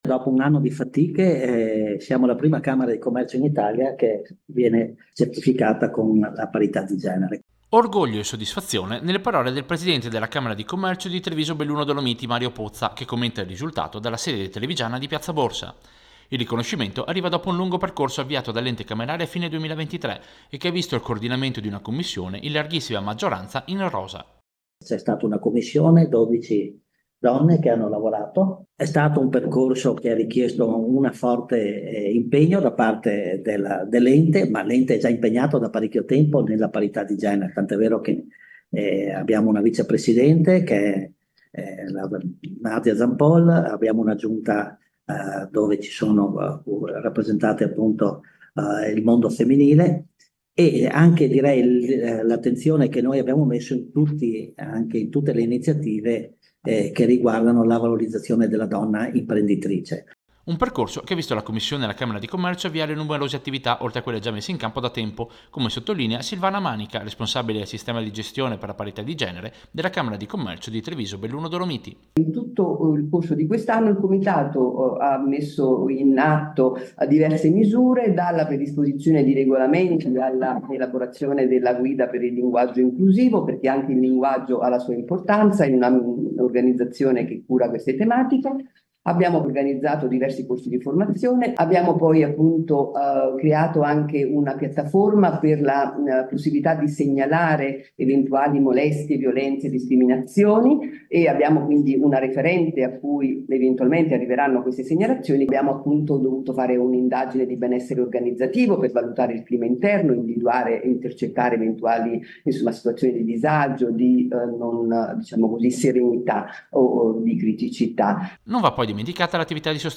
Servizio-Camera-Commercio-Parita-di-genere.mp3